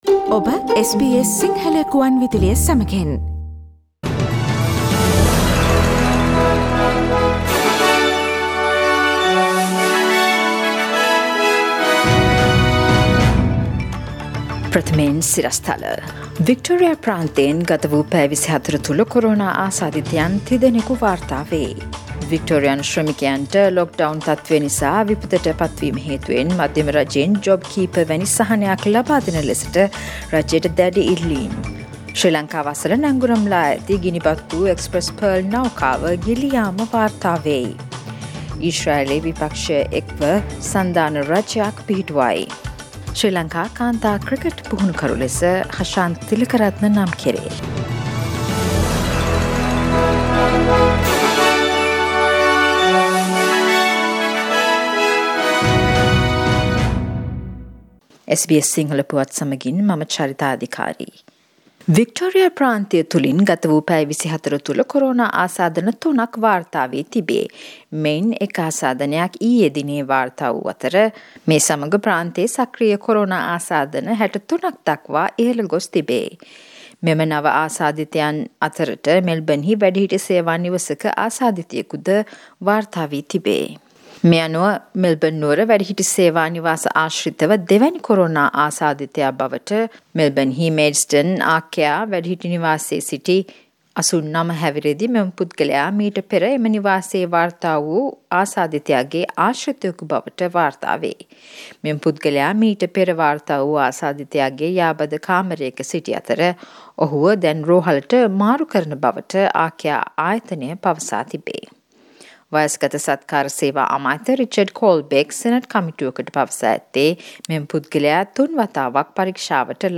සවන්දෙන්න, ඕස්ට්‍රේලියාවේ සහ ශ්‍රී ලංකාවේ අලුත්ම පුවත්, විදෙස් තොරතුරු සහ ක්‍රීඩා පුවත් රැගත් SBS සිංහල සේවයේ 2021 ජූනි මස 3 වන බ්‍රහස්පතින්දා වැඩසටහනේ ප්‍රවෘත්ති ප්‍රකාශයට.